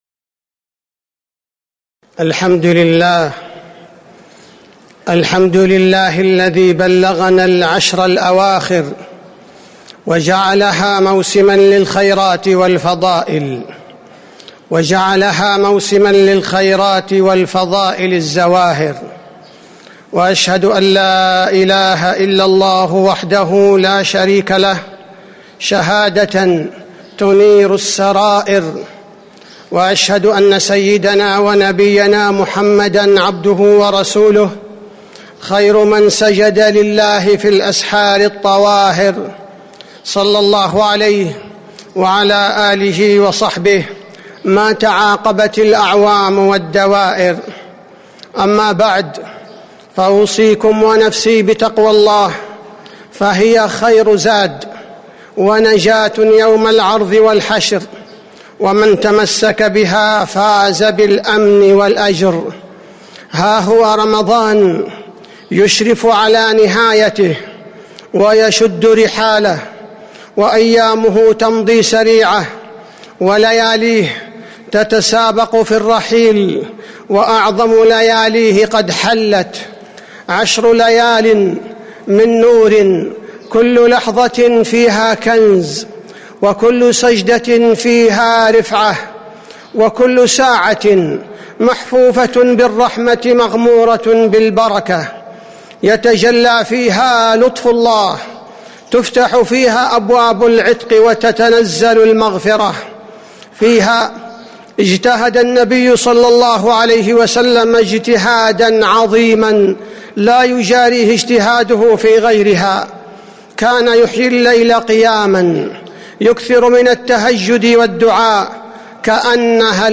تاريخ النشر ٢١ رمضان ١٤٤٦ هـ المكان: المسجد النبوي الشيخ: فضيلة الشيخ عبدالباري الثبيتي فضيلة الشيخ عبدالباري الثبيتي حال المؤمن في استقبال العشر الأواخر